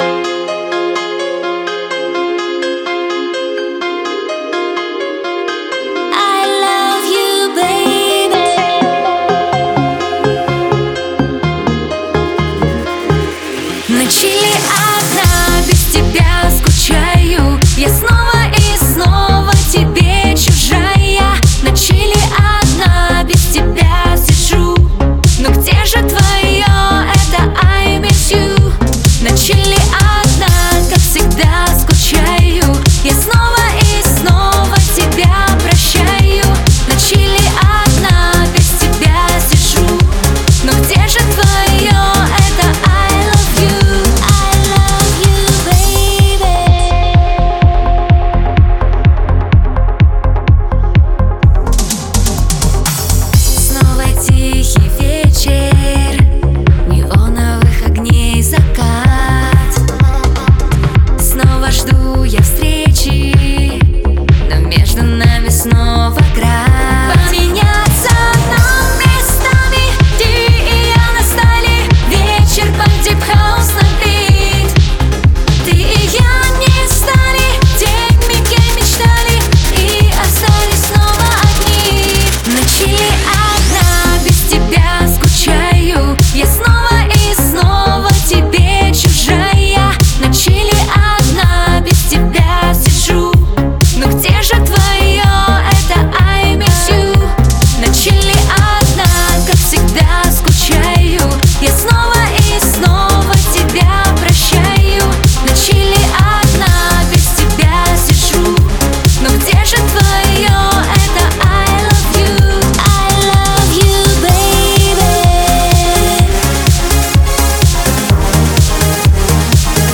Качает, охото плясать:)